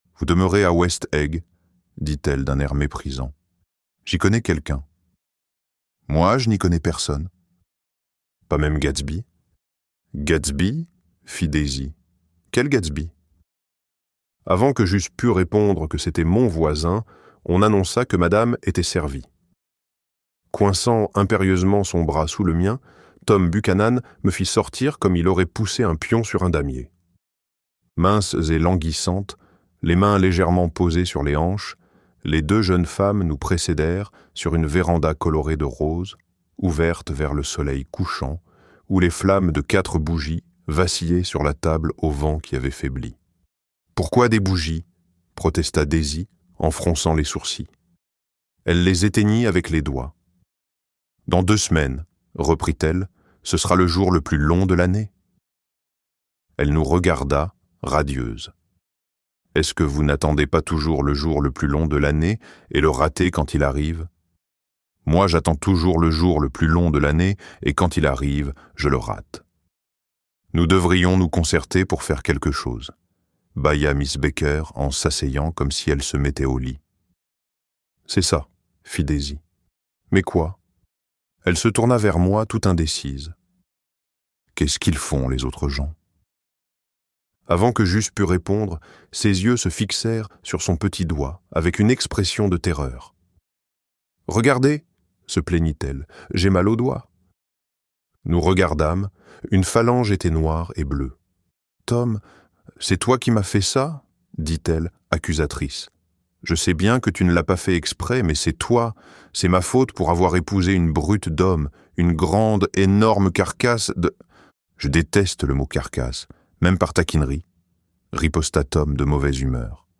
Gatsby le Magnifique - Livre Audio